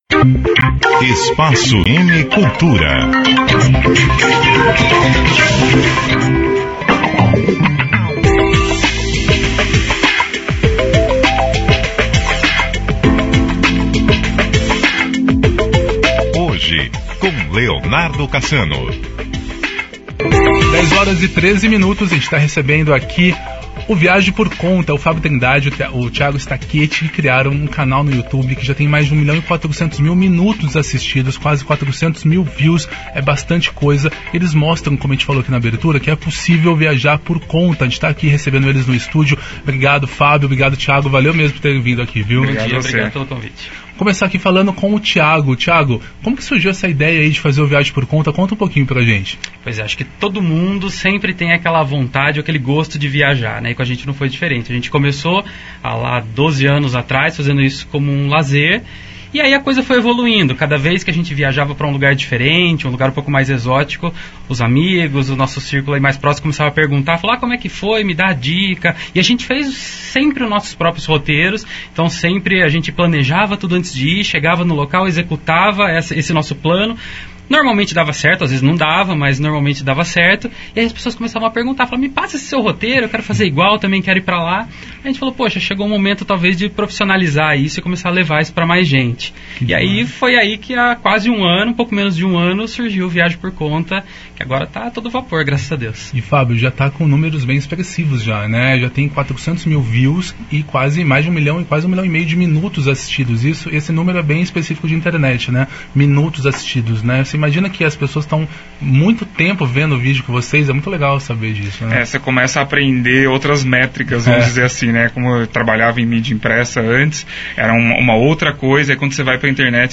Entrevista: Jornalistas contam os maiores perrengues que já passaram em dez anos de viagens
Entrevista-CBN-VIAJE-POR-CONTA.mp3